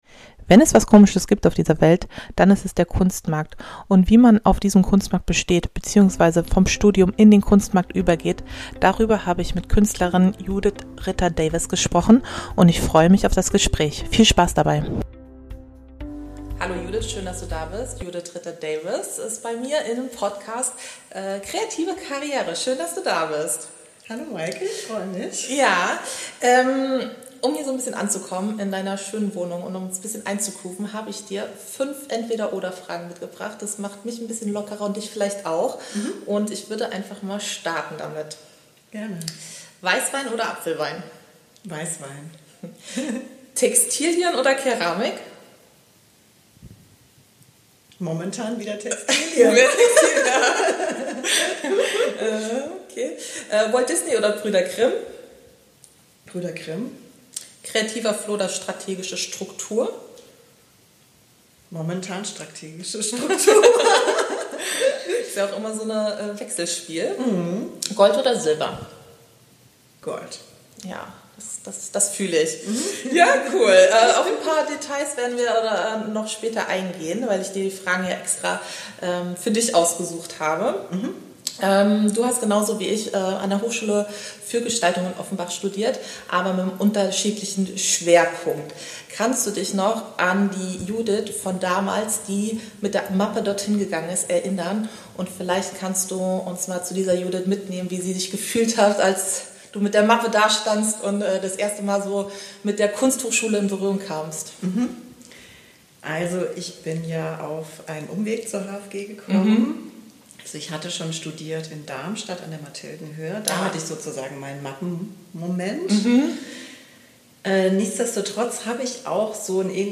Ein ehrliches, offenes Gespräch über Kreativität, Karriere und das, was dazwischen liegt.